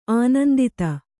♪ ānandita